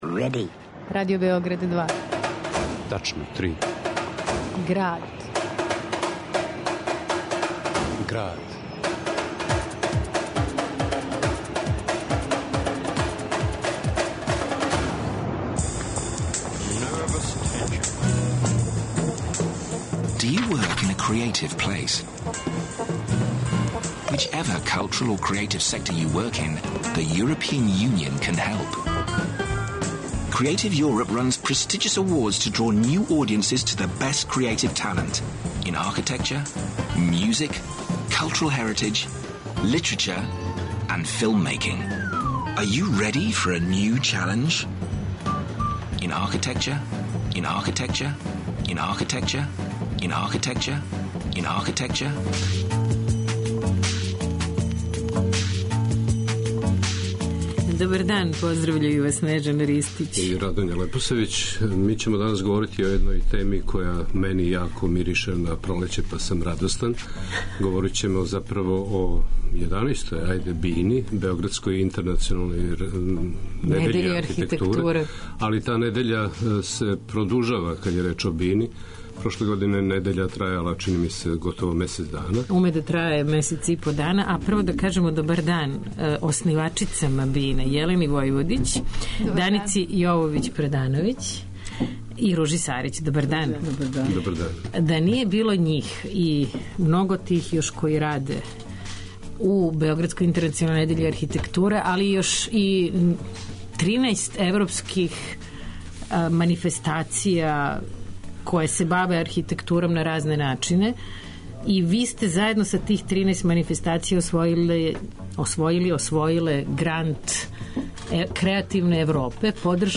уз документарне снимке